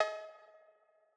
Blow Cowbell.wav